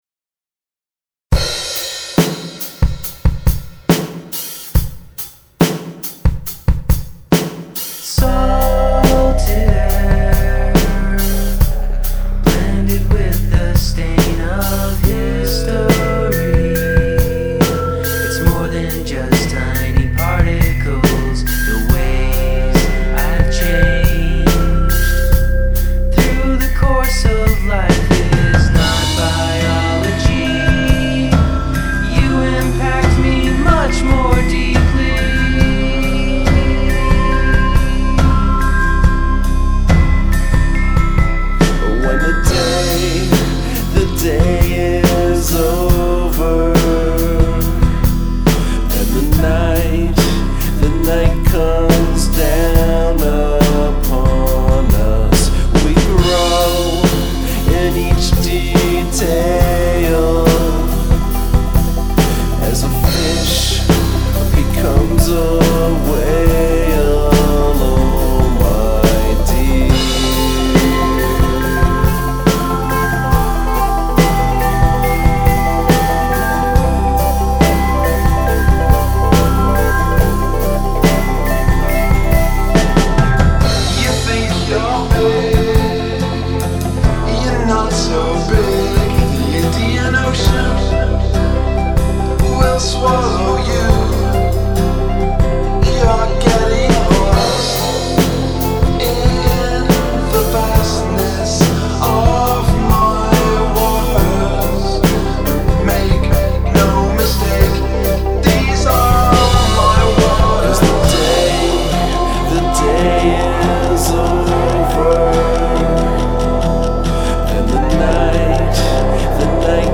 Music pleasant.